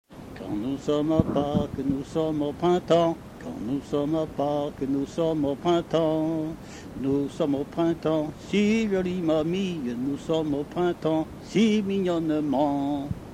danse : ronde : grand'danse
Pièce musicale inédite